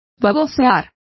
Complete with pronunciation of the translation of drool.